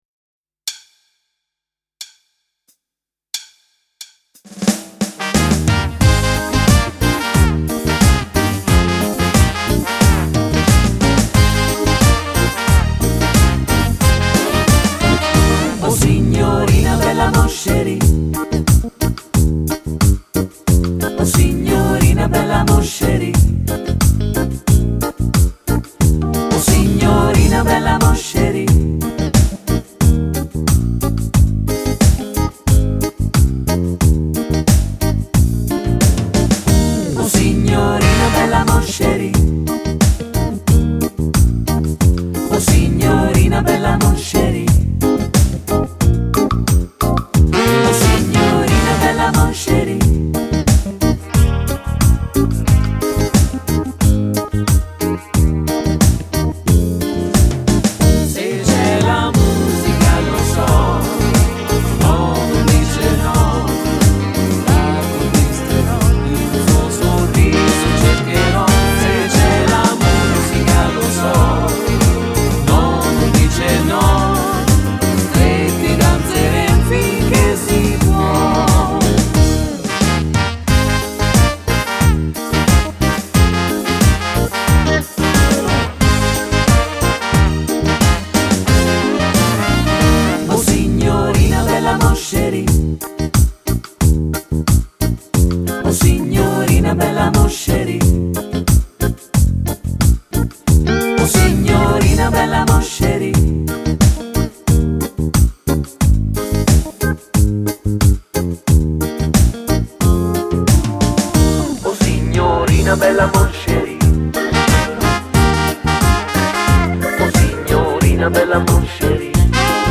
Bajon
Uomo